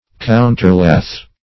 Counterlath \Coun"ter*lath`\, n. (Building)